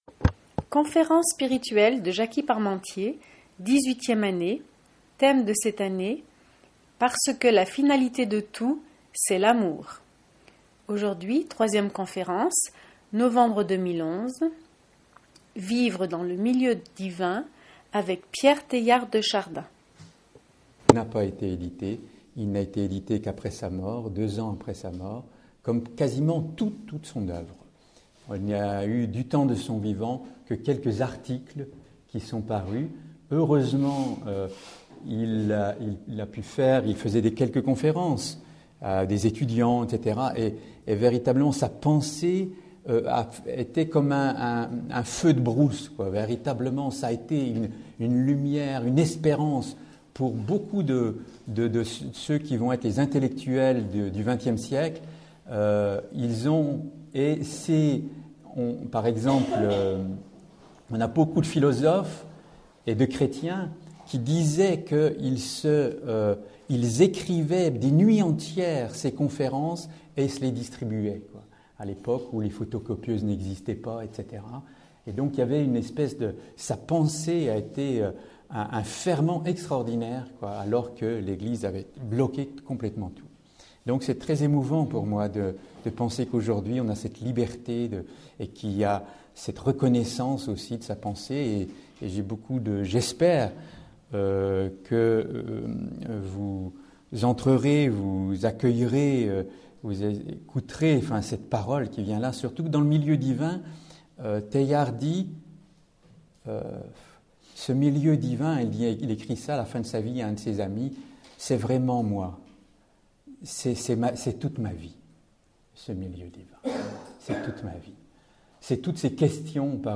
Toutes les conférences sont disponibles.